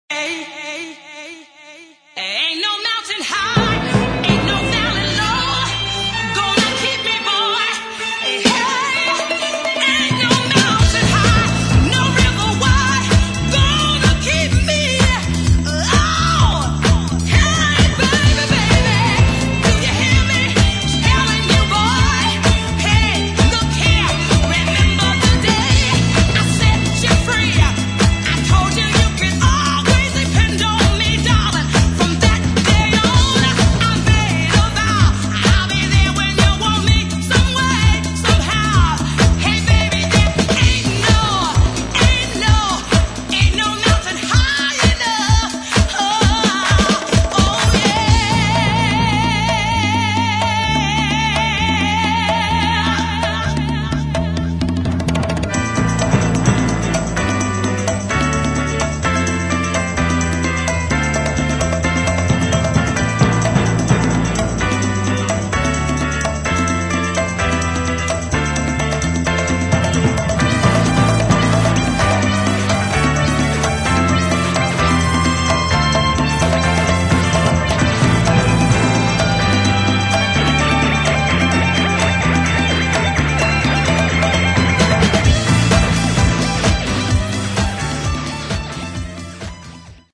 [ DEEP HOUSE | DISCO ]